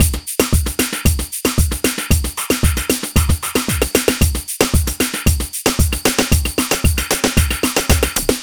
Ala Brzl 3 Drmz Dry 1a.wav